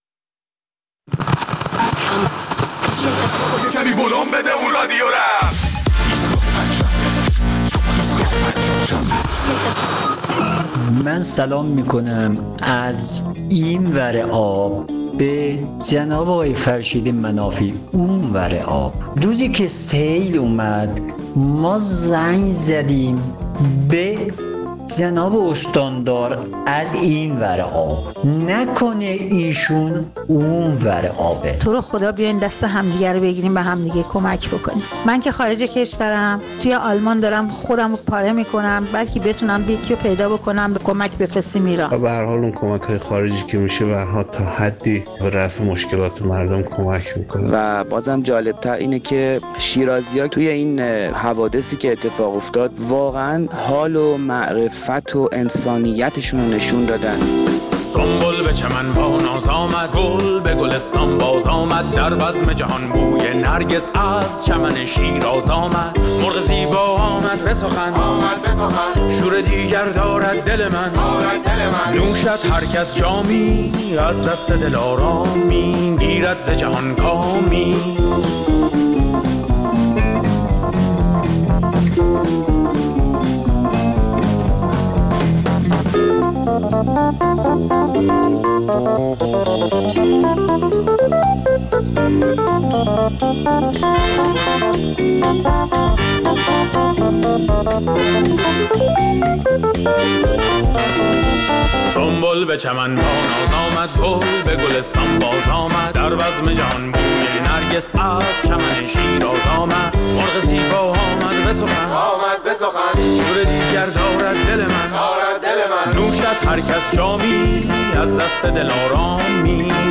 در این برنامه ادامه نظرات شنوندگان ایستگاه فردا را در مورد تفاوتهای نوروز ۹۸ با نوروزهای پیشین می‌شنویم.